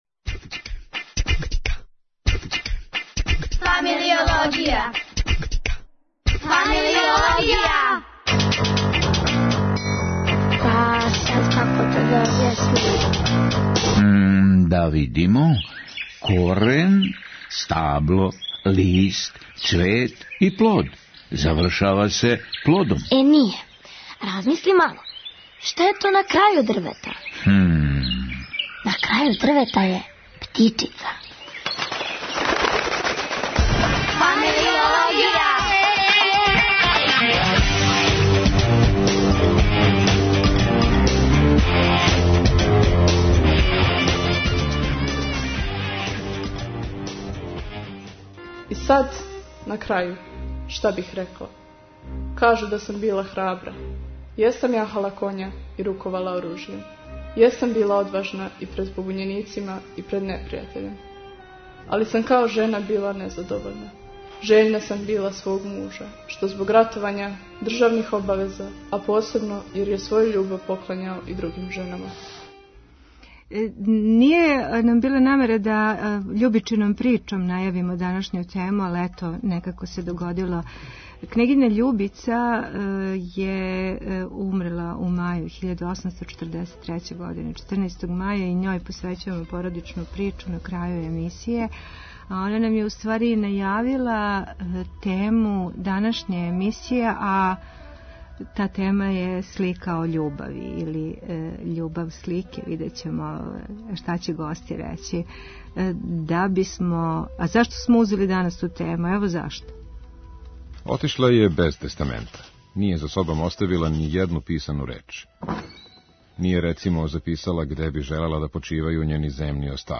Тему ће казивати млади.